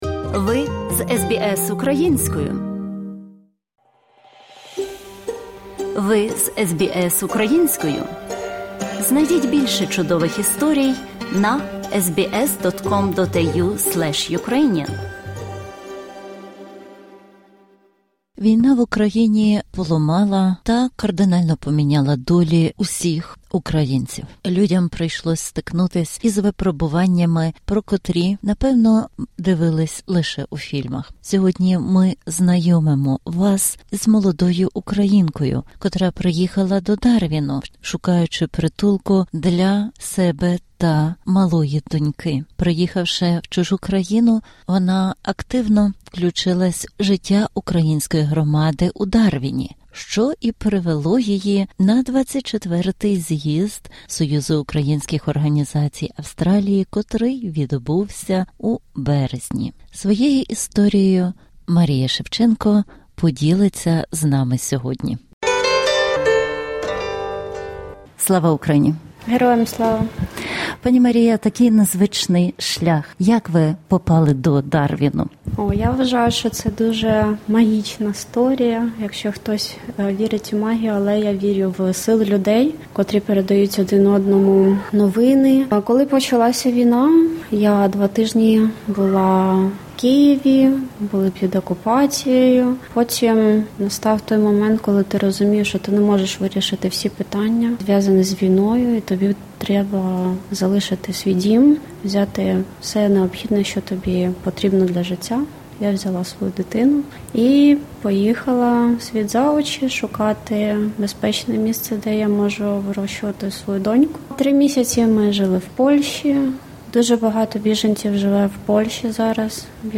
Щоб дізнатись більше історій, інтерв’ю та новин, перегляньте нашу колекцію подкастів SBS Українською.